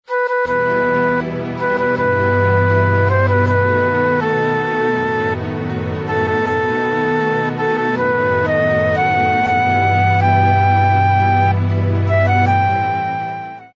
Заказ полифонической версии:
Послушать пример мелодии
• Пример мелодии содержит искажения (писк).